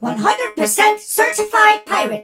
darryl_start_vo_05.ogg